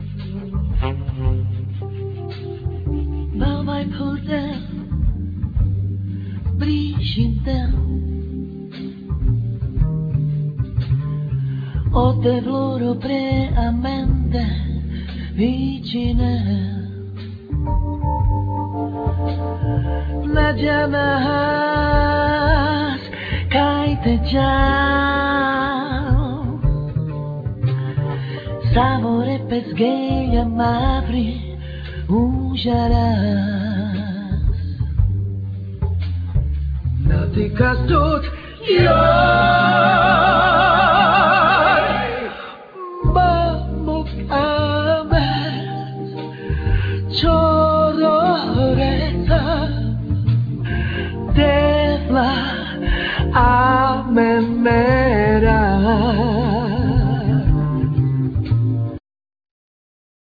Vocal,Cello
Guitars,Vocal
Piano,Fender piano model 88,Vocal
Accordion
Violin
Bass-guitar,Double bass
Drums